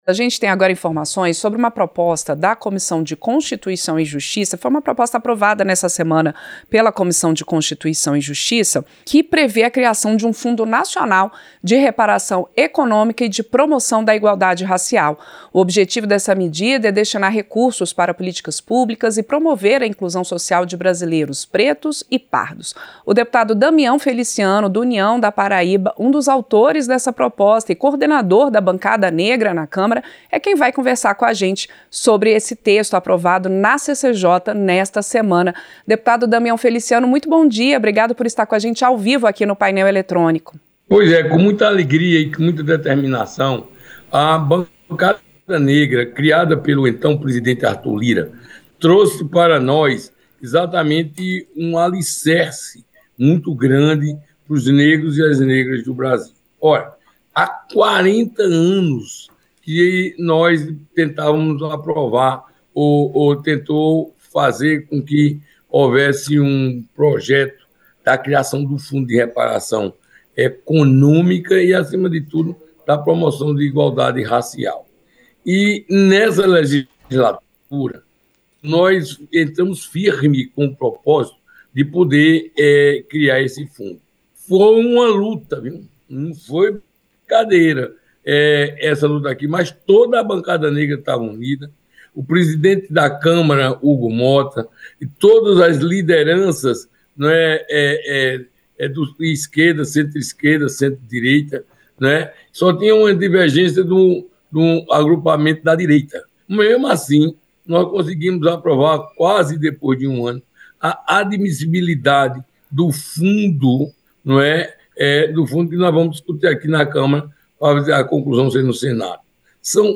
• Entrevista - Dep. Damião Feliciano (União-PB)
Programa ao vivo com reportagens, entrevistas sobre temas relacionados à Câmara dos Deputados, e o que vai ser destaque durante a semana.